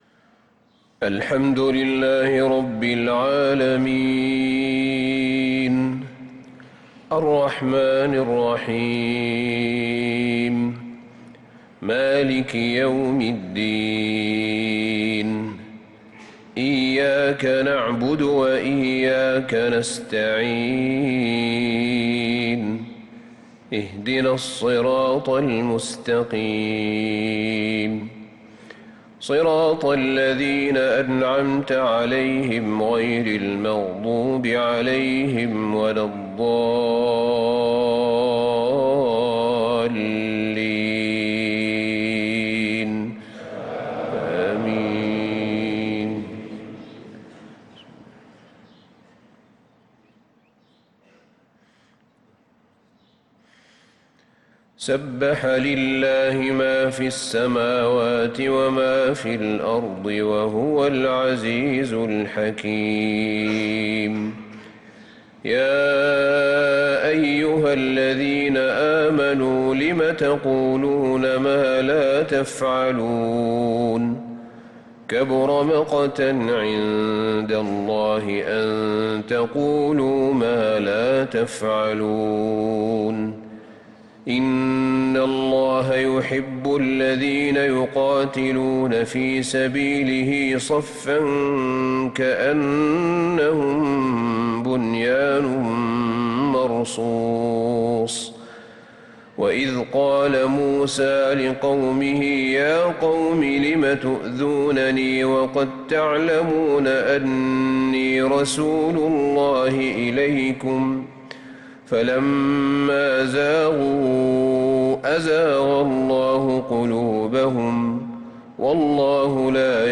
صلاة الفجر للقارئ أحمد بن طالب حميد 14 محرم 1446 هـ